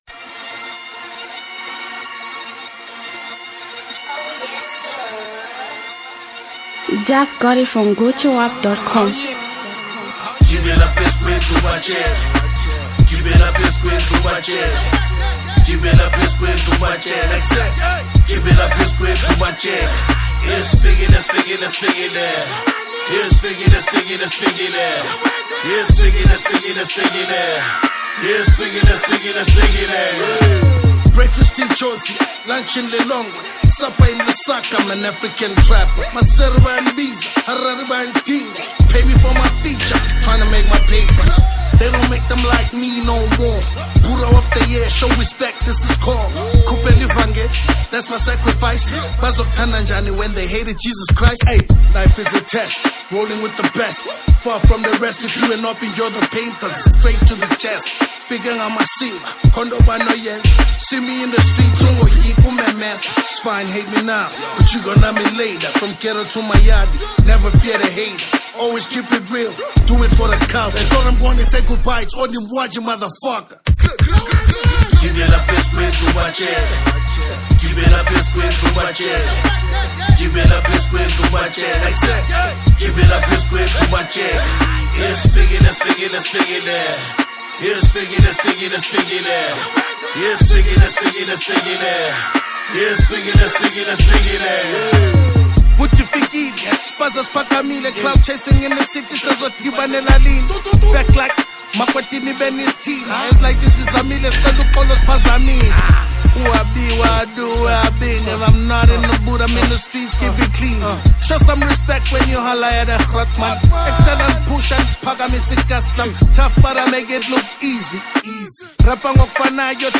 South African hip-hop
buzzing street anthem